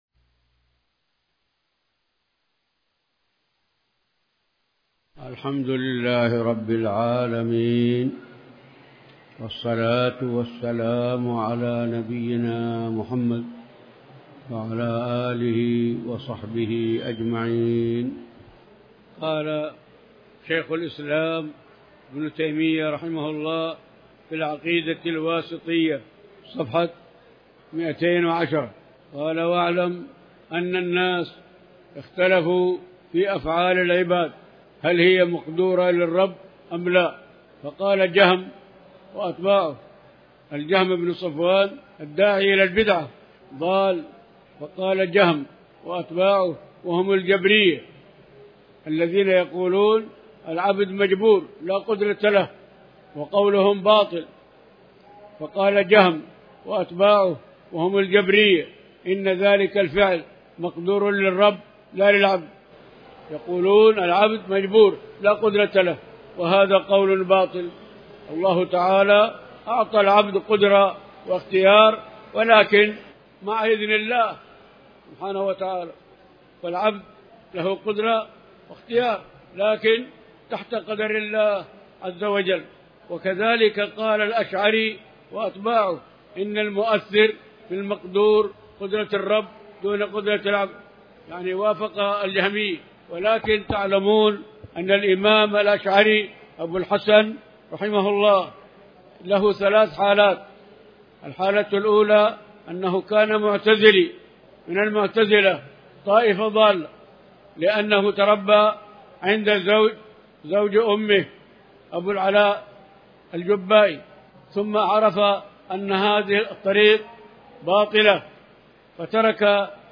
تاريخ النشر ١٣ محرم ١٤٤٠ هـ المكان: المسجد الحرام الشيخ